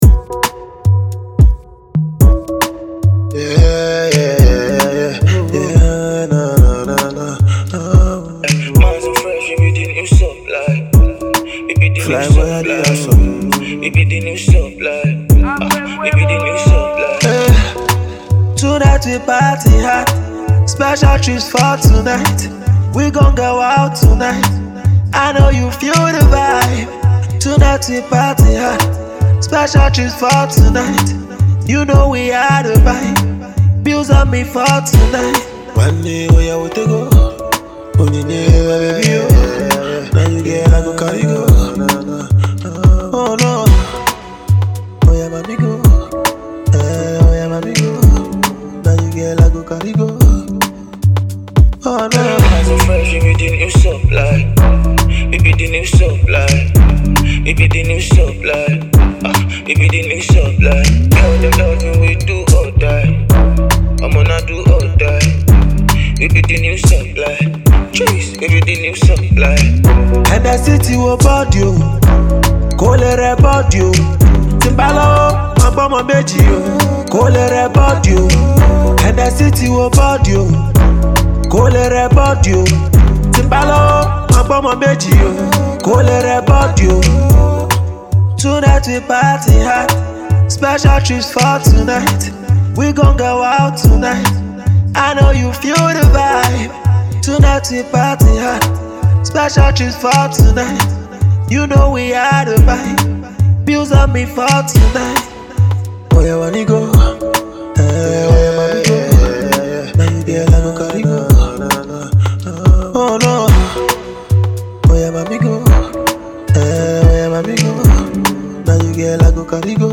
mid-tempo bop